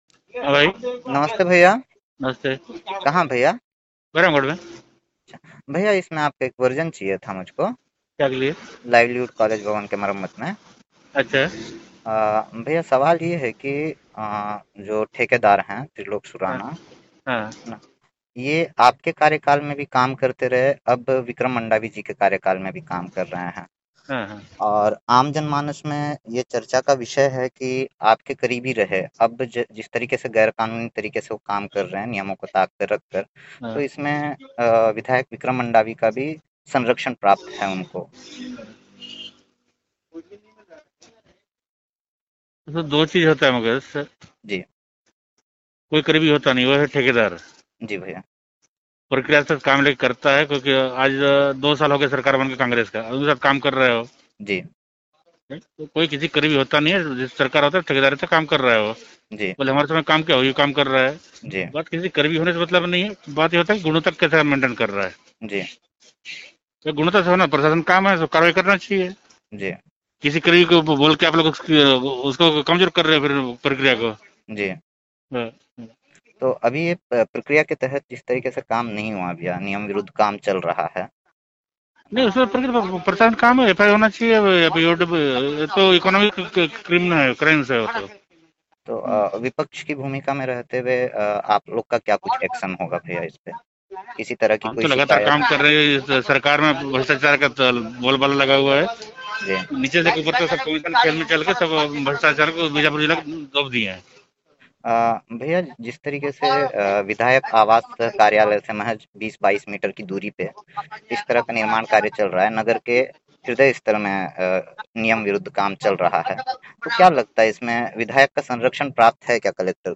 बाईट – महेश गागड़ा ।